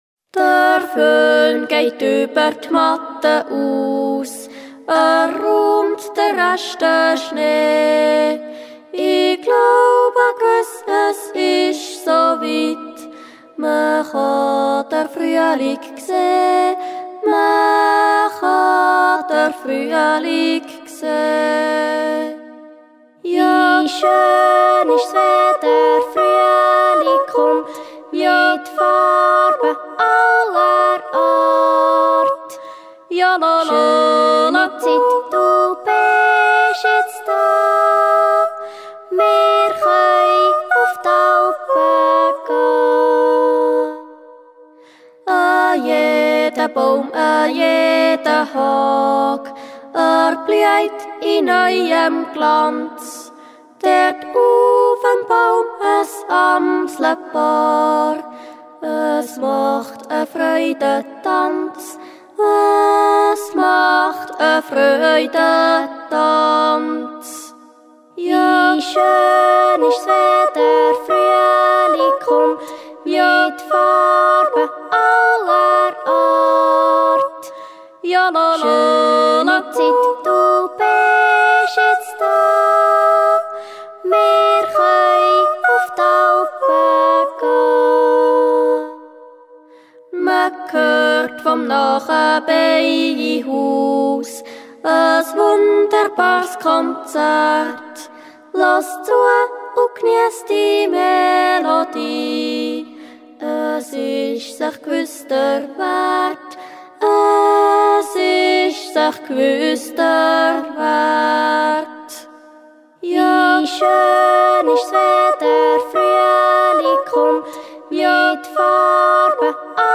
Yodel songs.
These songs tell us a lot about social life in the countryside and the relation between man and nature throughout the centuries. You hear interpretations ranging from duets to full ensemble.
I ha dr Früelig gseh, Yodel Song
Bäichle Jodlerfamilie